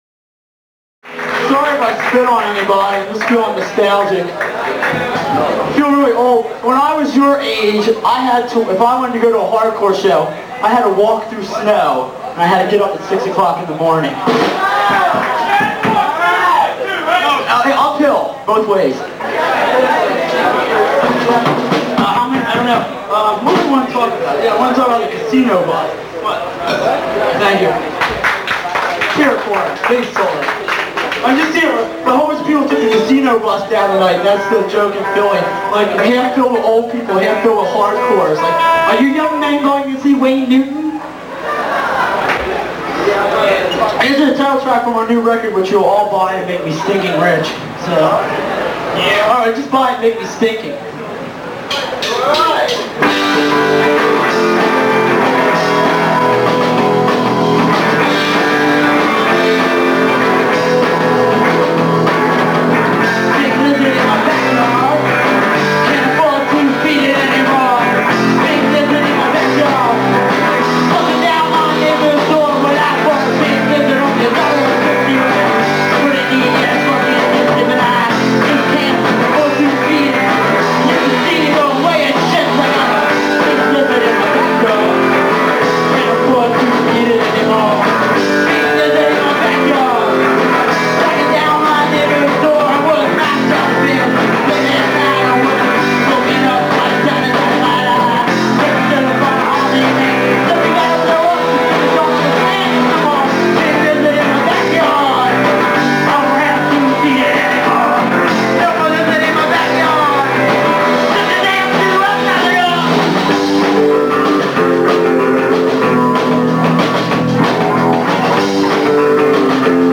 Elk’s Lodge Atlantic City 1985?